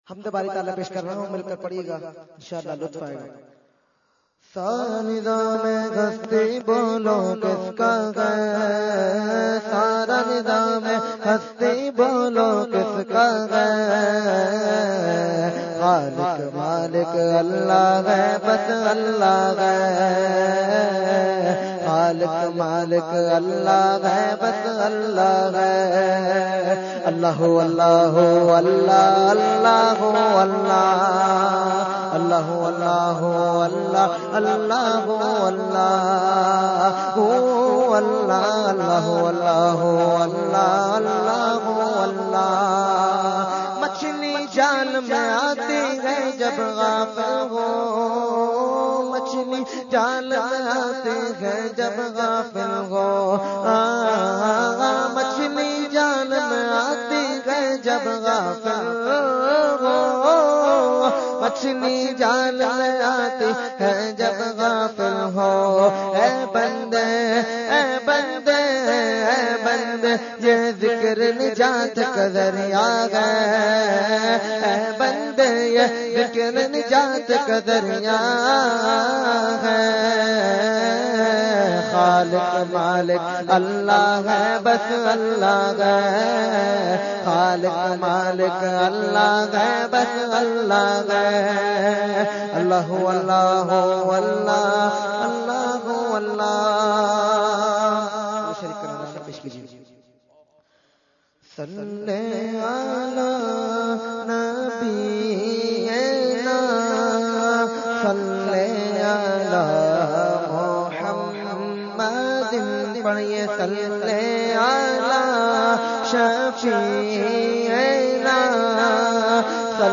Category : Hamd | Language : UrduEvent : Mehfil PECHS Society Khi 2015